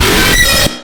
Ambushjumpscare.mp3